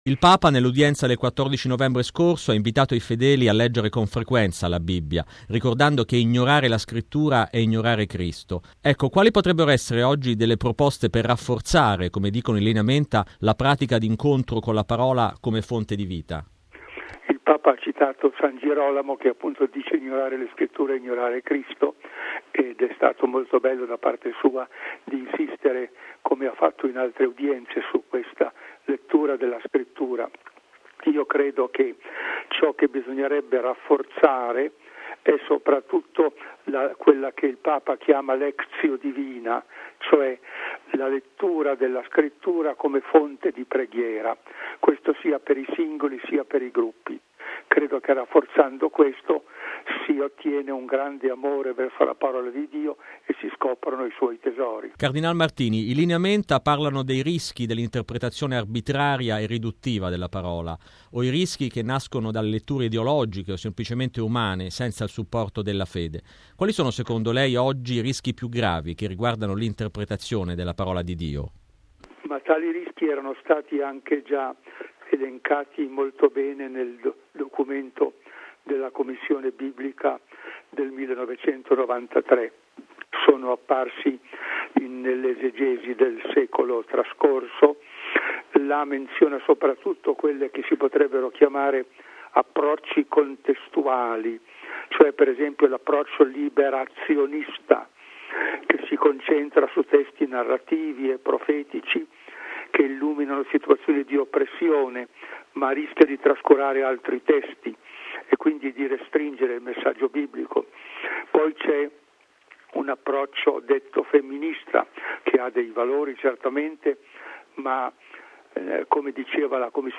Intervista con il cardinale Martini sul Sinodo convocato dal Papa sul tema della Parola di Dio